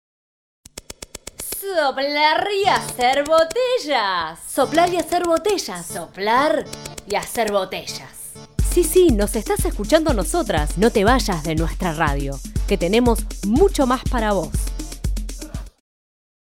Locución
Separador de programa radial